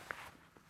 cloth_2.ogg